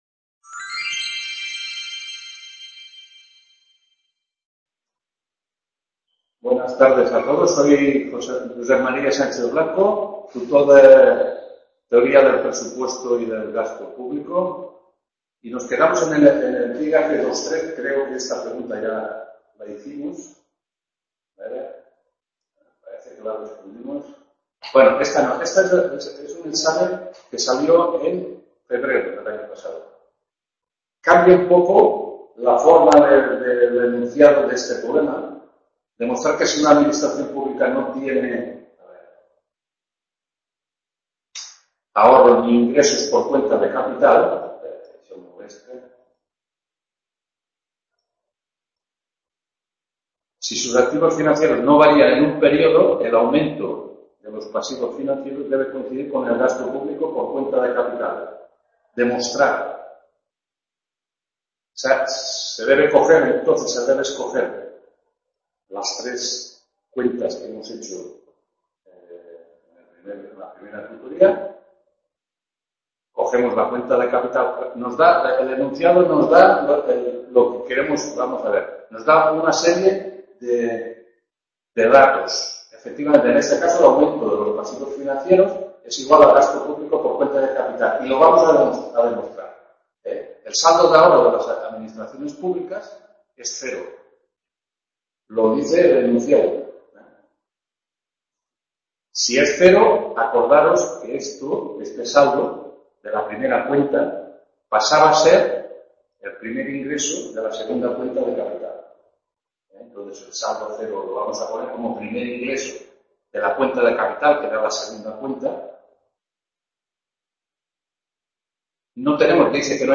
2ª TUTORÍA TEORÍA DEL PRESUPUESTO Y DEL GASTO PÚBLICO…